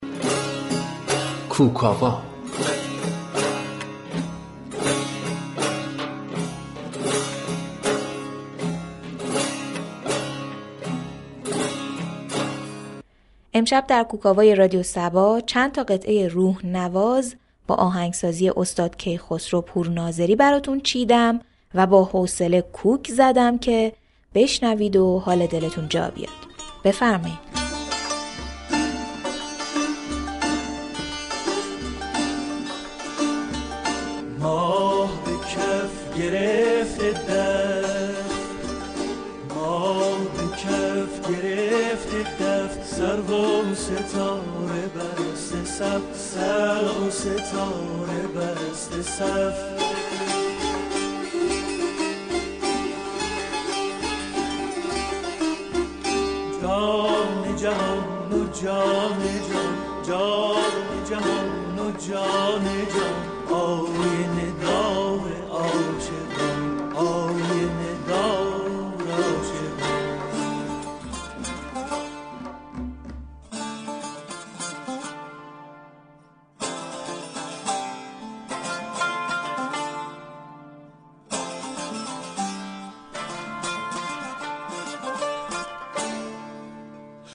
با تركیب متن، ترانه و موسیقی
قطعات موسیقی باكلام
ساز تنبور